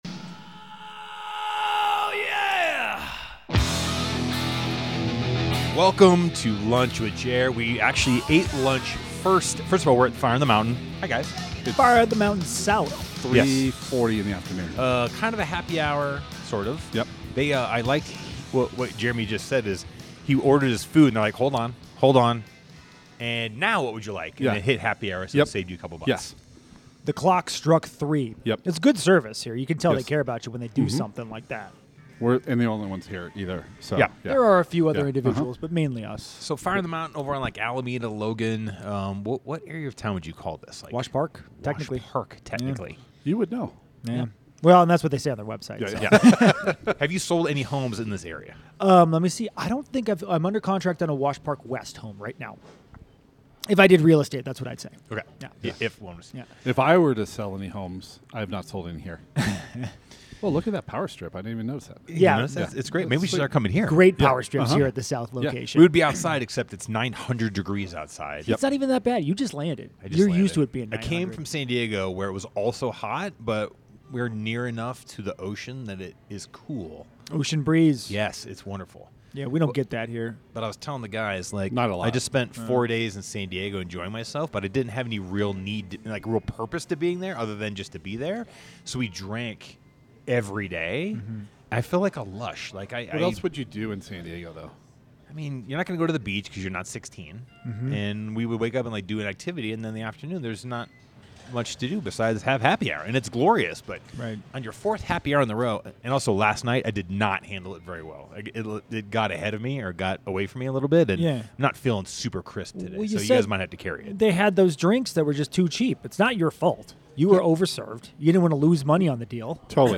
The gentlemen meet at Fire on the Mountain south to discuss a wide array of topics, but the most notable is the WNBA, which happens at the end of the podcast.